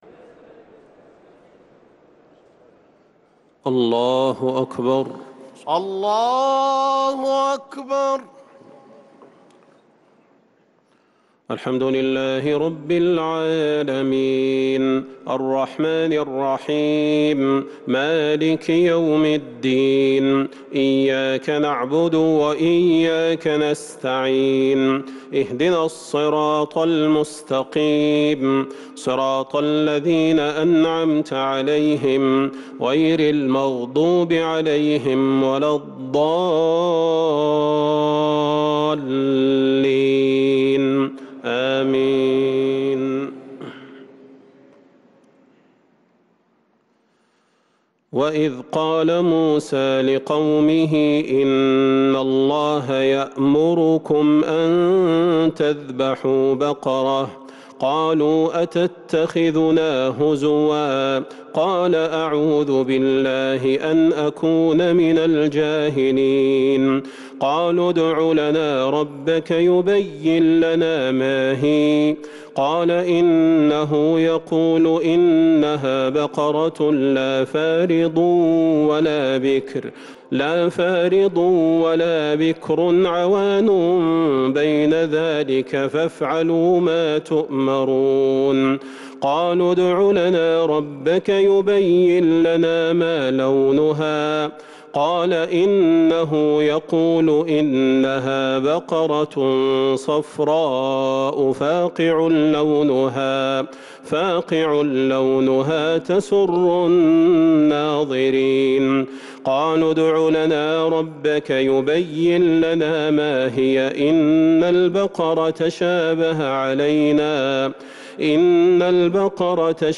تراويح ليلة 1 رمضان 1443 من سورة البقرة {67-105} Taraweeh 1st night Ramadan 1443H > تراويح الحرم النبوي عام 1443 🕌 > التراويح - تلاوات الحرمين